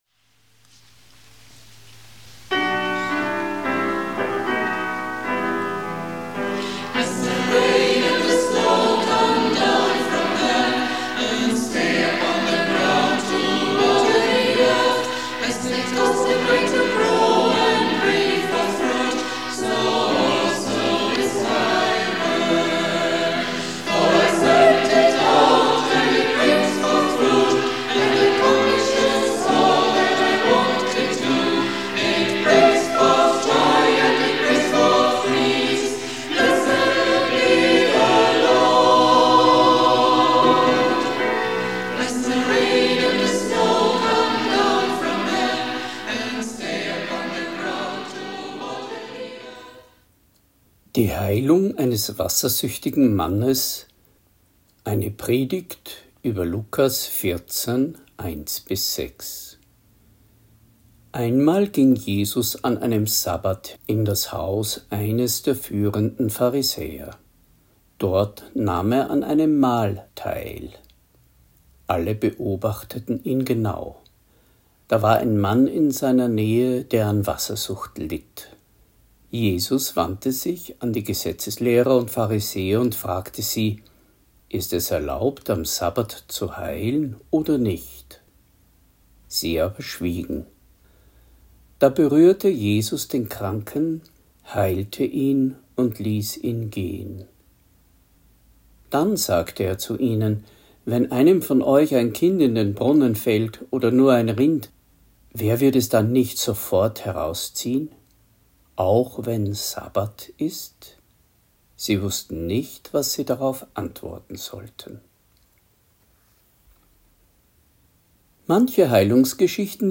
Predigt | NT03 Lukas 14,1-6 Die Heilung eines Wassersüchtigen